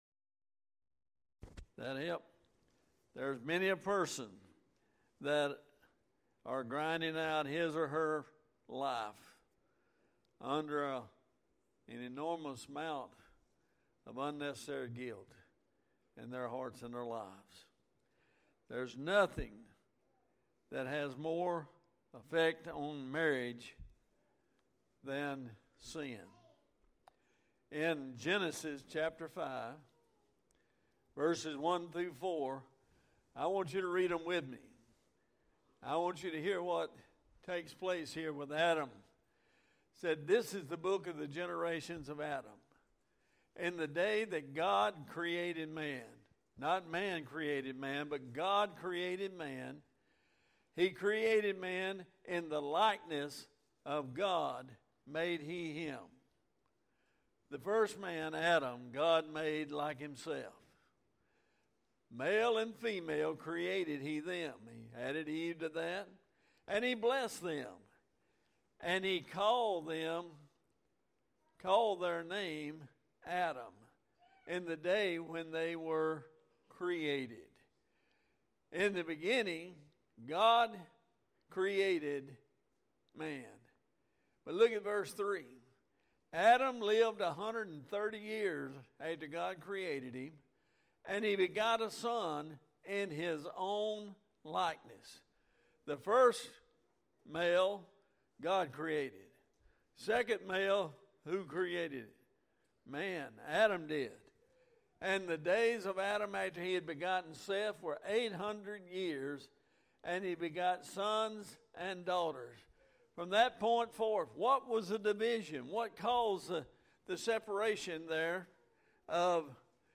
Part 3 AM Sermon Qualified Servants 1 Corinthians 7:1-15